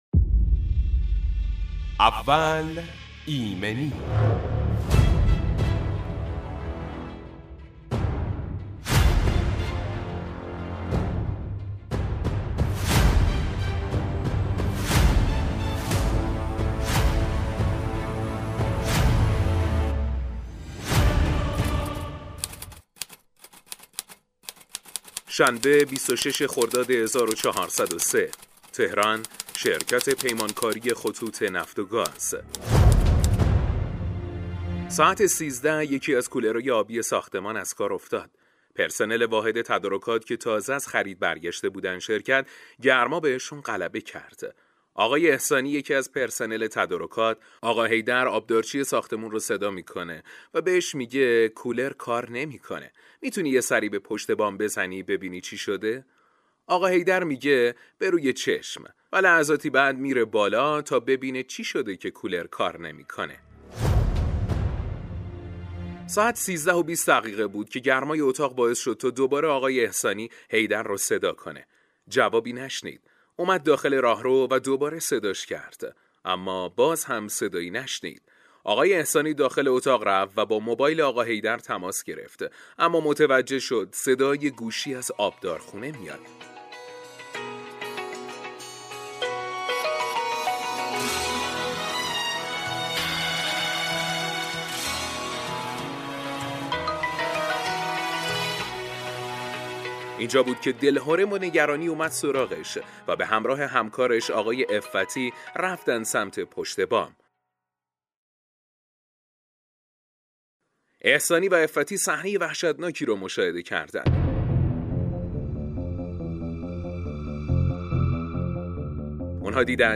(صدای زنگ موبایل)
برنامه اول ایمنی به مدت ۱۵ دقیقه با حضور کارشناس متخصص آغاز و تجربیات مصداقی ایمنی صنعتی به صورت داستانی بیان می شود.